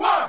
Tm8_Chant69.wav